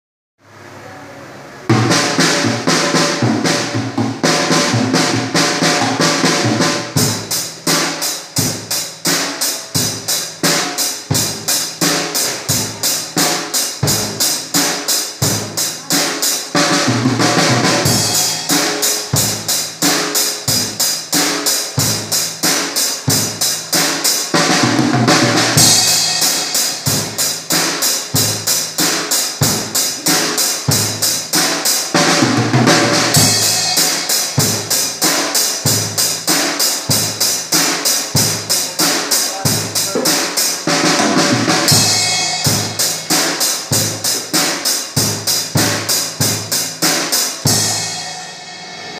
Na bateria